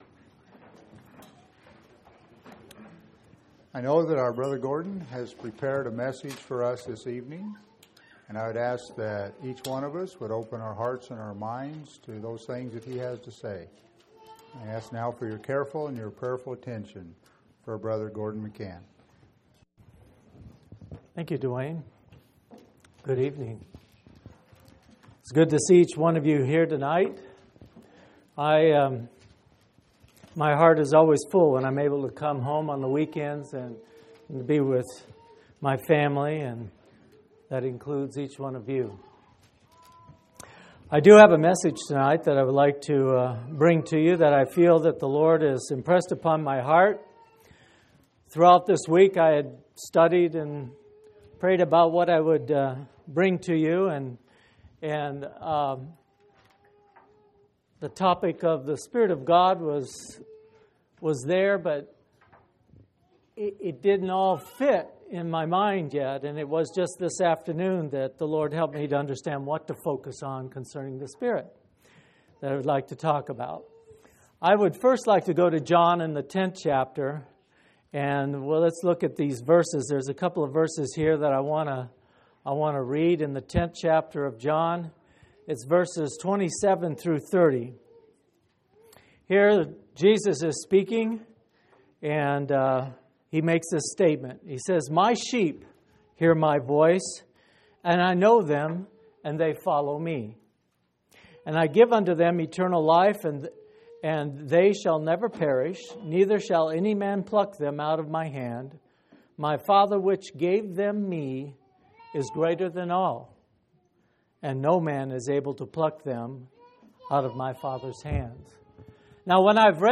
12/18/2005 Location: Phoenix Local Event